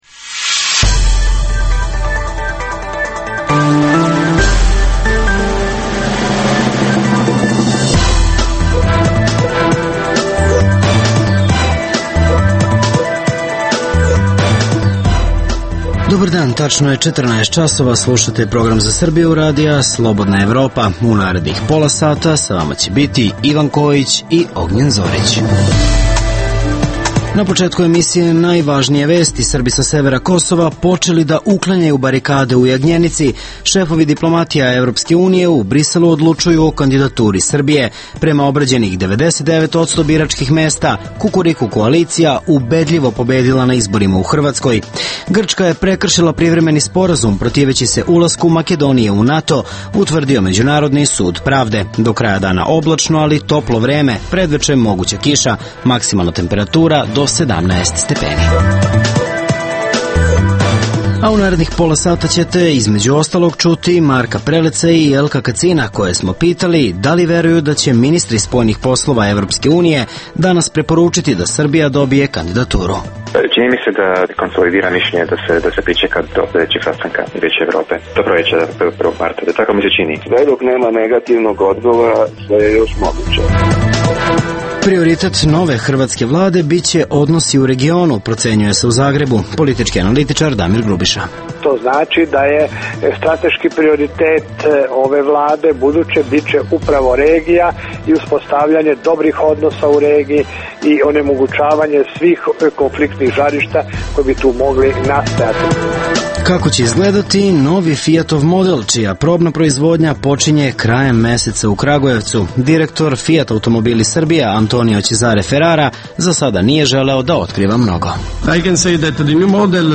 U emisiji poslušajte: - Srbi sa severa Kosova, počeli da uklanjaju barikade u Jagnjenici, javljaju reporteri RSE sa lica mesta. - Šefovi diplomatija Evropske unije, u Briselu odlučuju o kandidaturi Srbije.